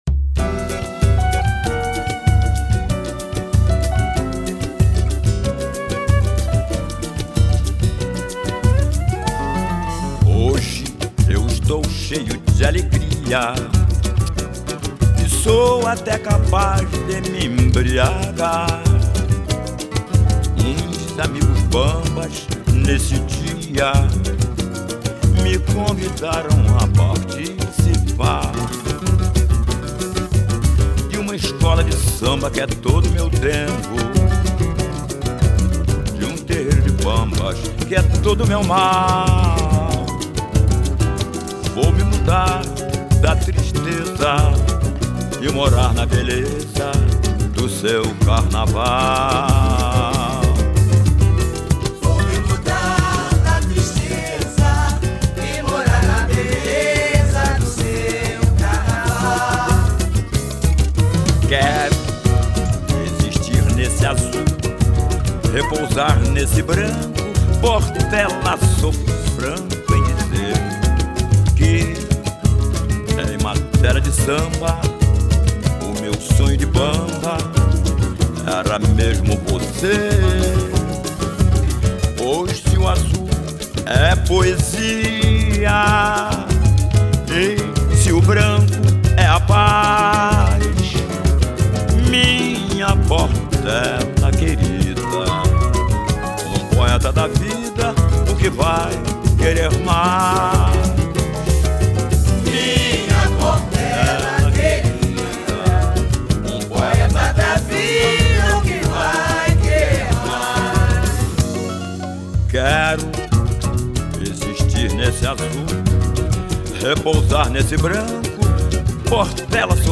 Samba exaltação